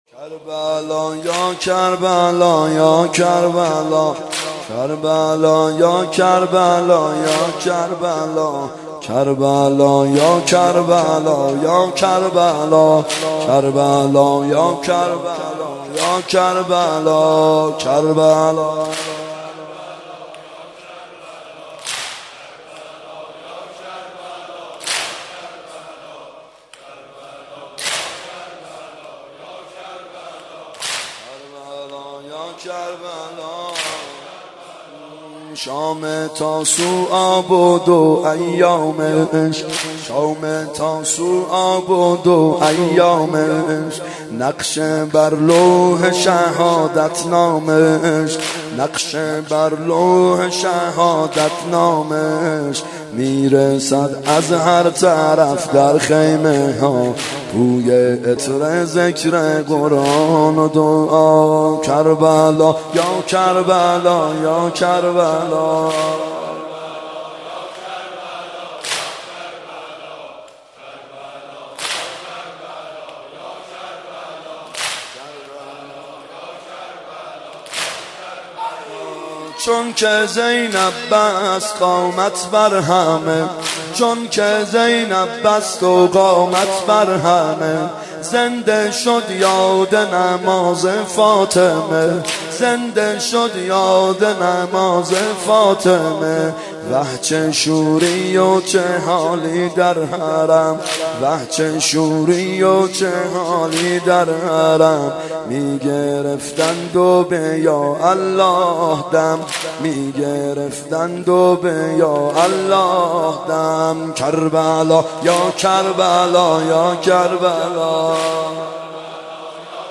محرم 92 ( هیأت یامهدی عج)